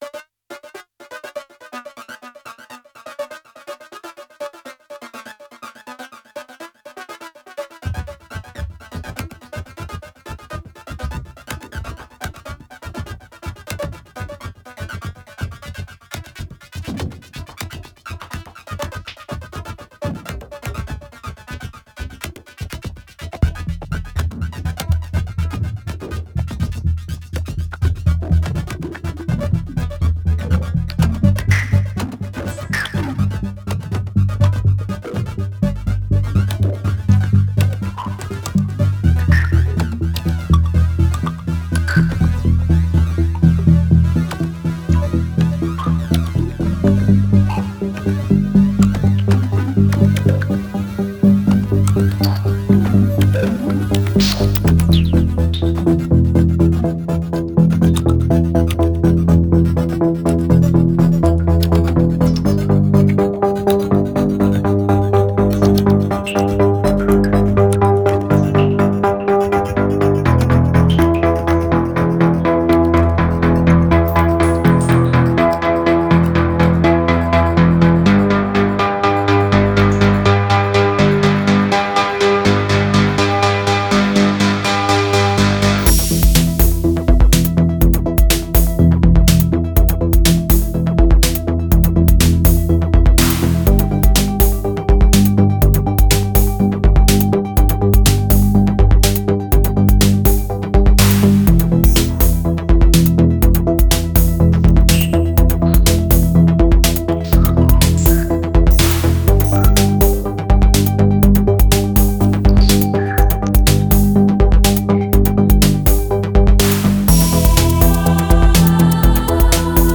Genre: House , Trance , Spacesynth , Synthpop , Electronic.